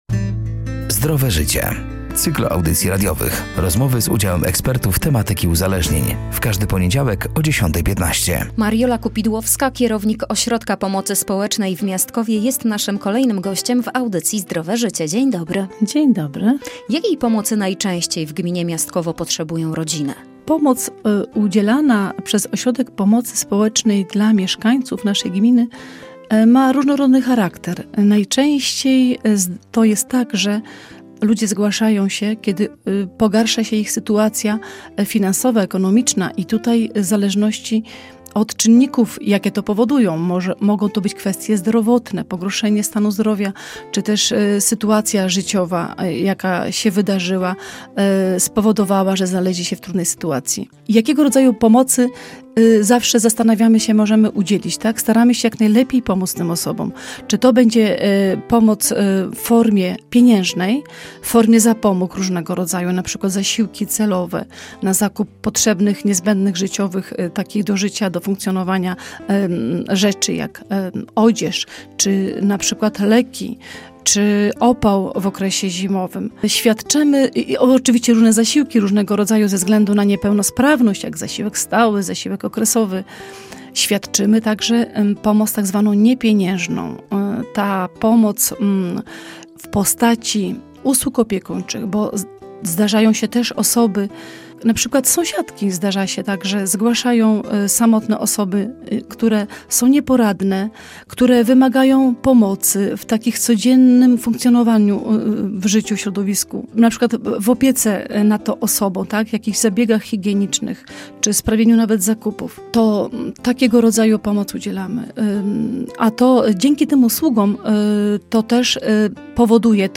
„Zdrowe Życie” to cykl audycji radiowych. Rozmowy z udziałem ekspertów tematyki uzależnień.